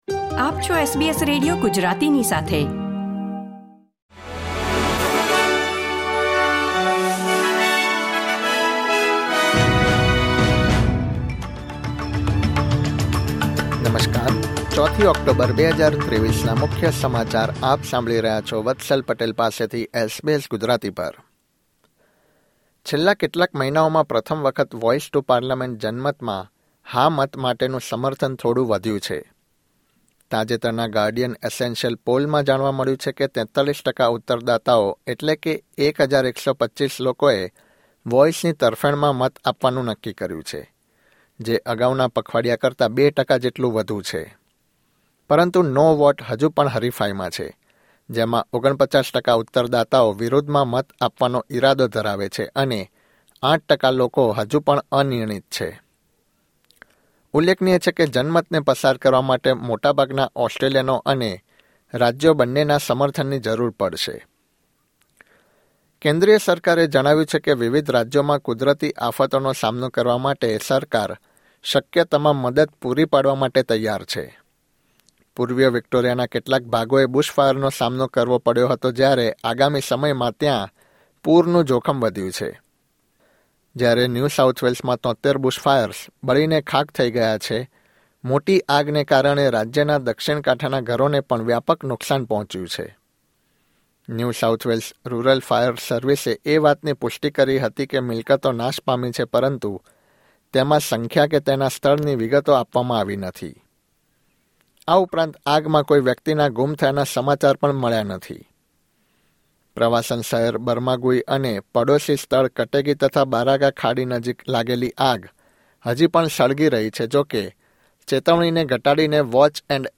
SBS Gujarati News Bulletin 4 October 2023